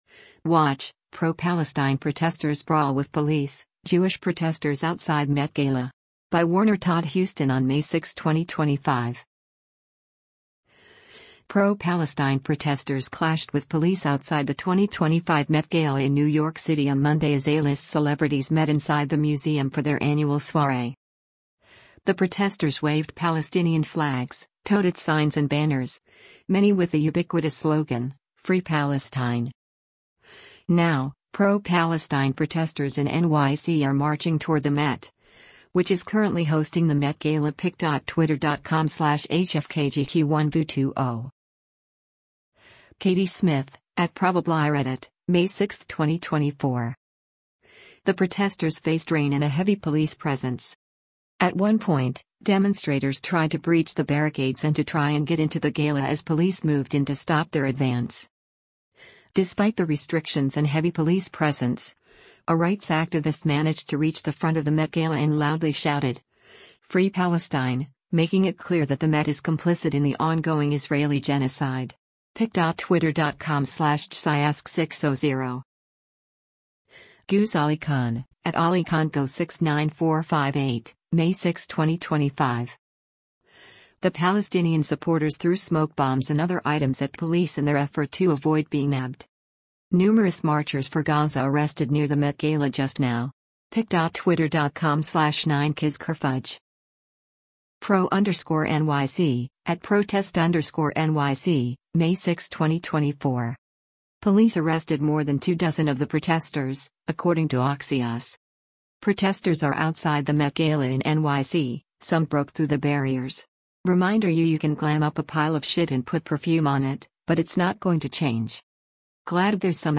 Watch: Pro-Palestine Protesters Brawl With Police, Jewish Protesters Outside Met Gala
Pro-Palestine protesters clashed with police outside the 2025 Met Gala in New York City on Monday as A-list celebrities met inside the museum for their annual soiree.
The protesters faced rain and a heavy police presence.